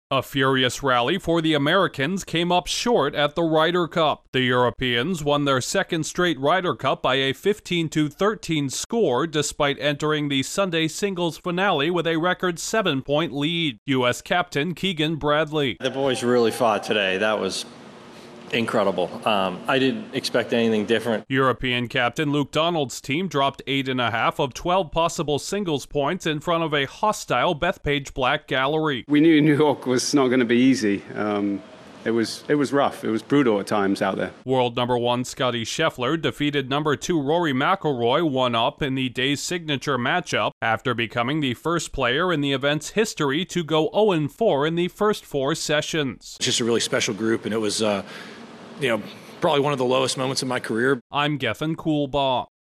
The Ryder Cup will remain in Europe despite a spirited finish for the Americans. Correspondent